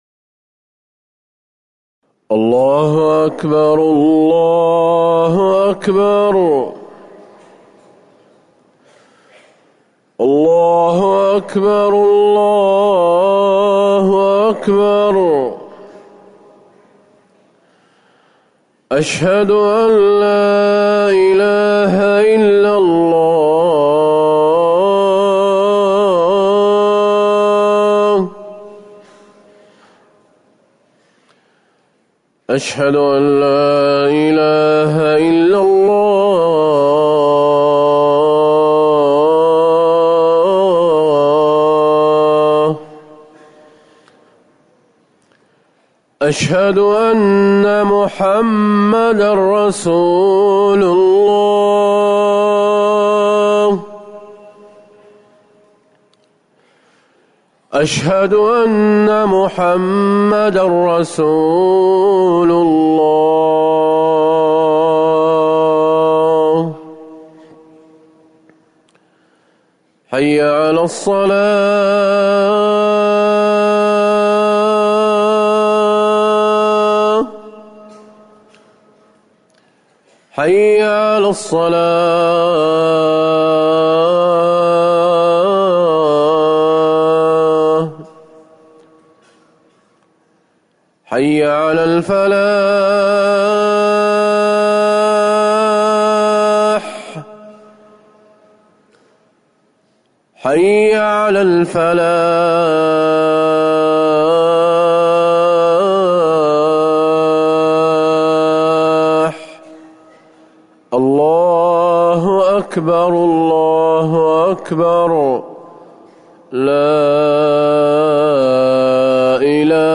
أذان الفجر الأول - الموقع الرسمي لرئاسة الشؤون الدينية بالمسجد النبوي والمسجد الحرام
تاريخ النشر ٣ صفر ١٤٤١ هـ المكان: المسجد النبوي الشيخ